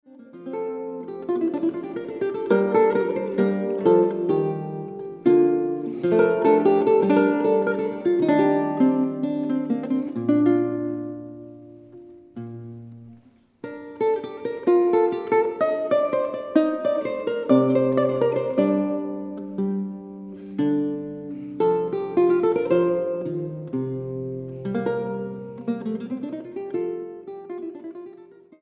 Laute